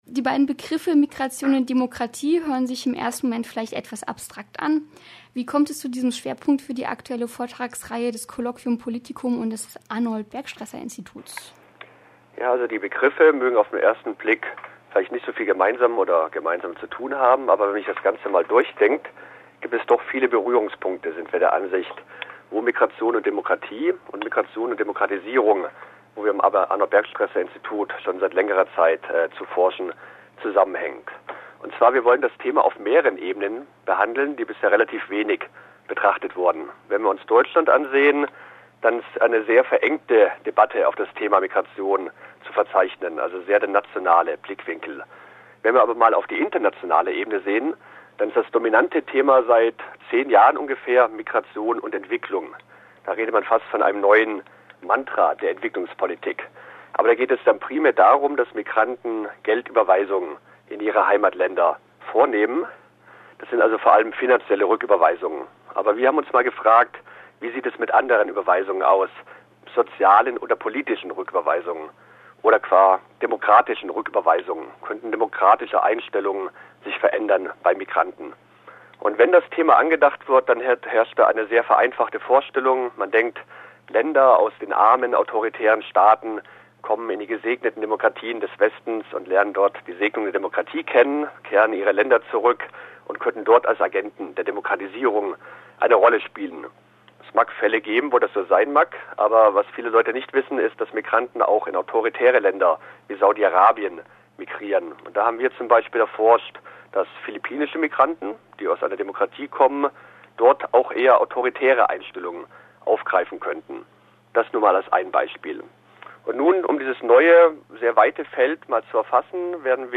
Mittagsmagazin mit Veranstaltungshinweisen für das Dreyeckland.